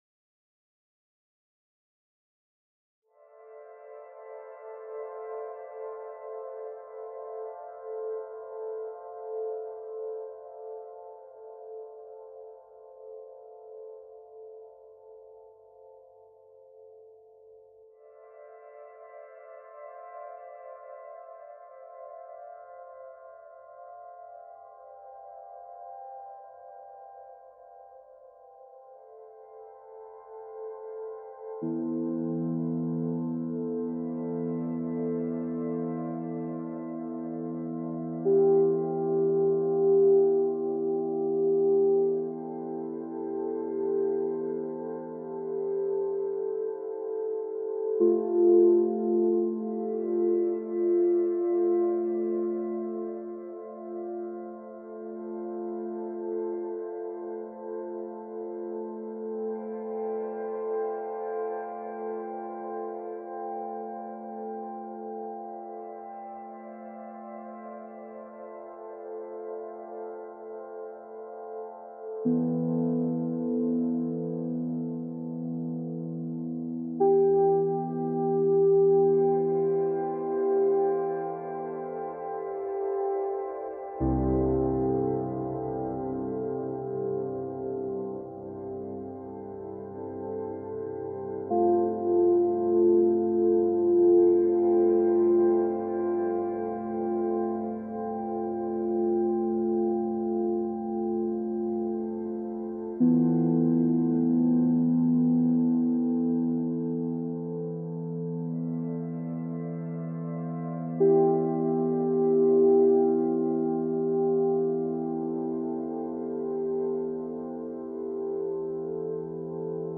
In Sympathy Music for CymaPhone A portfolio of sympathetic resonance compositions enlightened by the mystical forms of rāga and sound healing.